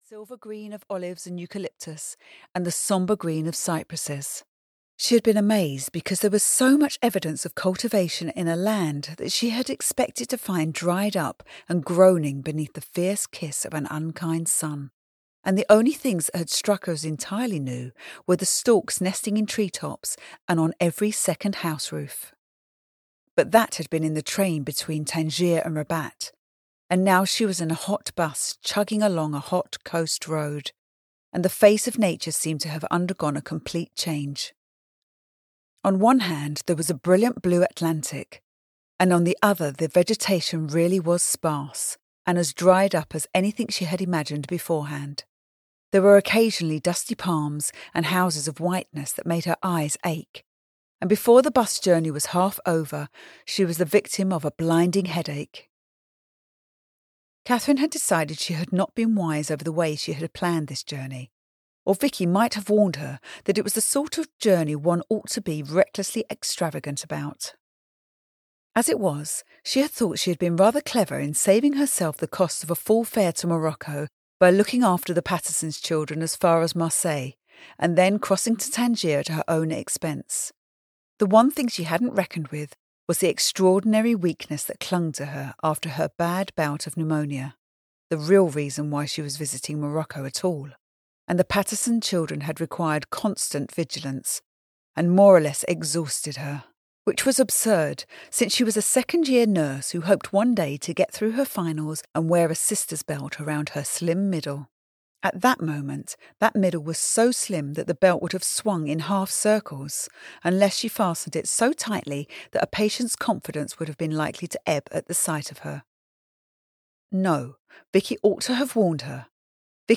Sweet Barbary (EN) audiokniha
Ukázka z knihy